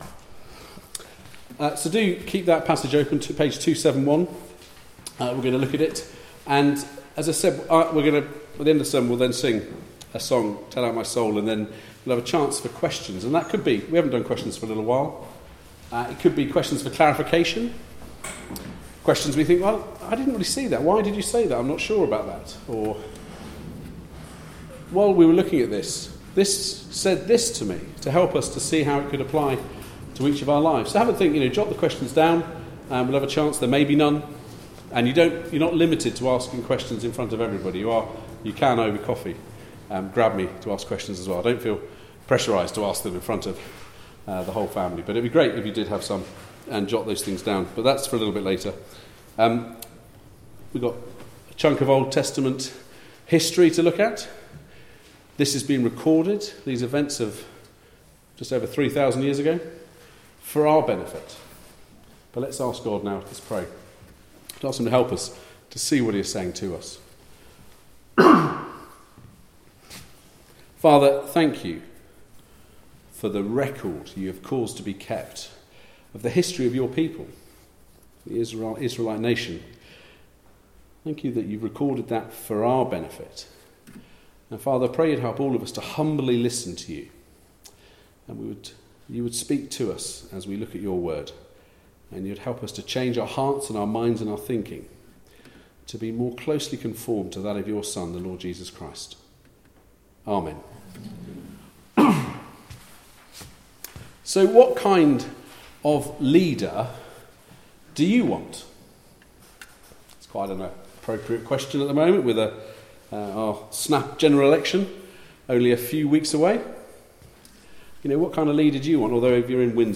Passage: 1 Samuel: 1 v1-2 v11 Service Type: Weekly Service at 4pm